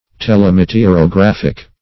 Search Result for " telemeteorographic" : The Collaborative International Dictionary of English v.0.48: Telemeteorograph \Tel`e*me`te*or"o*graph\, n. [Gr. th^le far + meteorograph.]
telemeteorographic.mp3